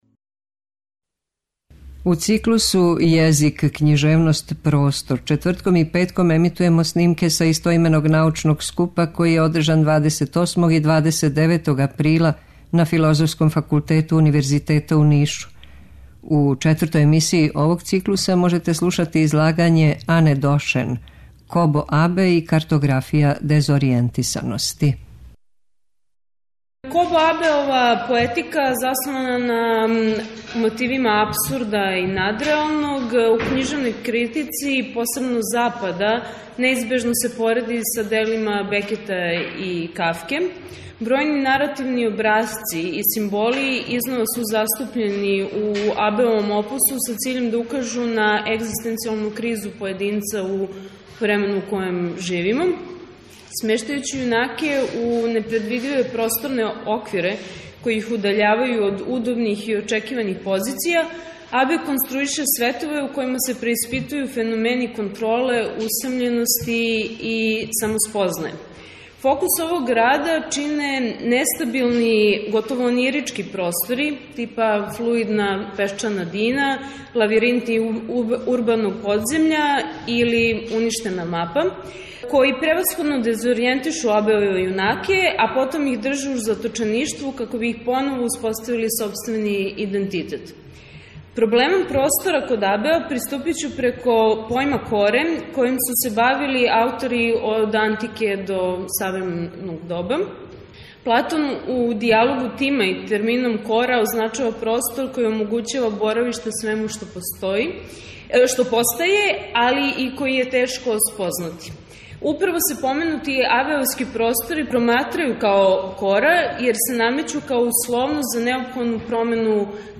У циклусу ЈЕЗИК, КЊИЖЕВНОСТ, ПРОСТОР четвртком и петком ћемо емитовати снимке са истoименог научног скупа, који је одржан 28. и 29. априла на Филозофском факултету Универзитета у Нишу.
Научни скупoви